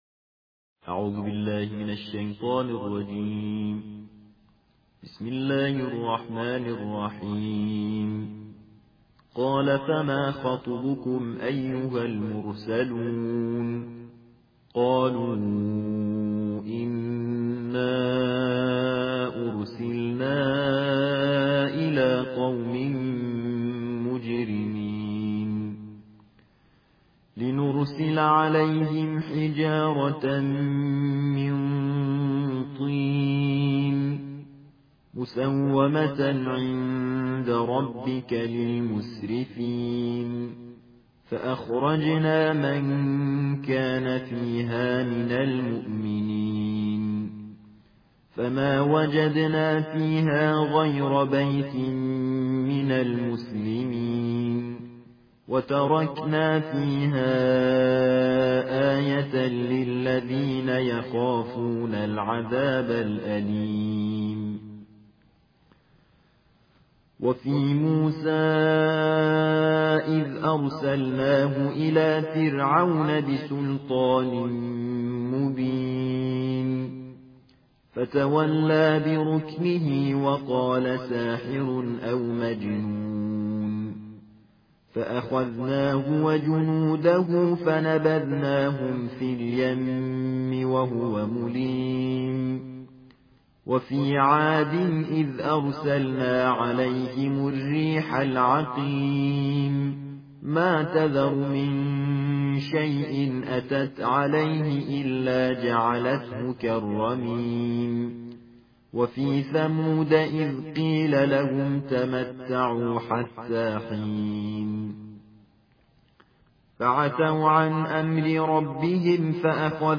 ترتیل جزء بیست‌وهفتم قرآن